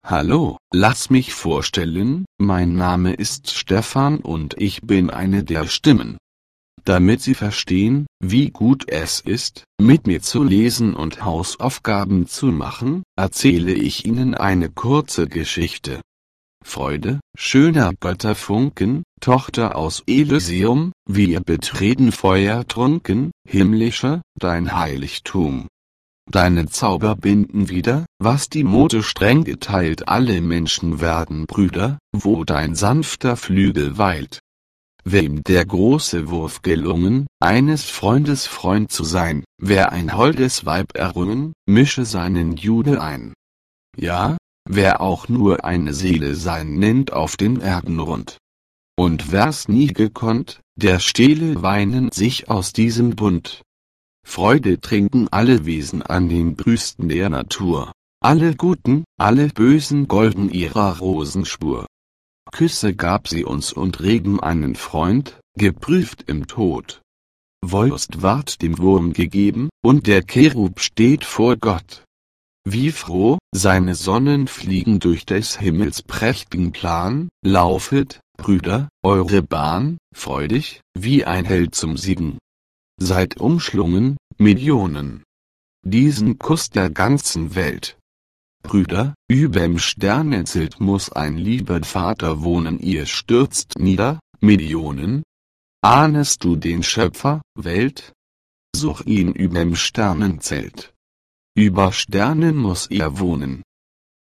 Per questo motivo, la voce risulta estremamente gradevole, chiara e fluente e priva del timbro metallico tipico delle voci artificiali.
Voce tedesca Stefan
Stefan-lenta.mp3